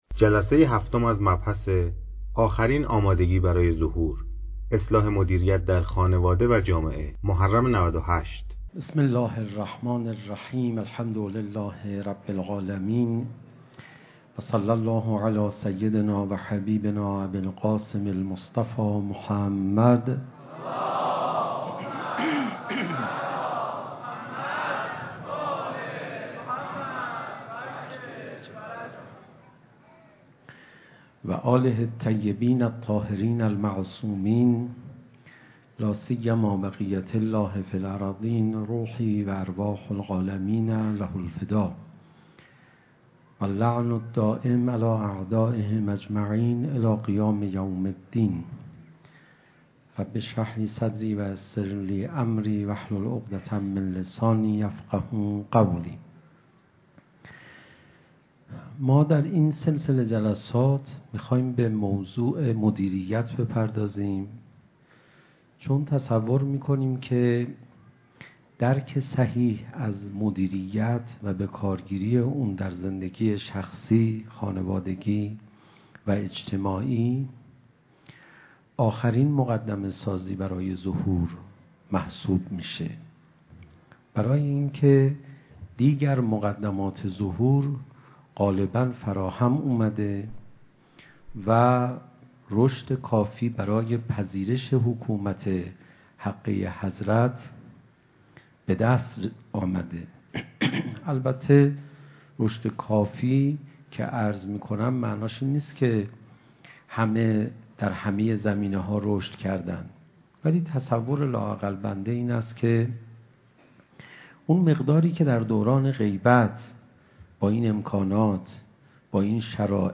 مراسم عزاداری شب هفتم محرم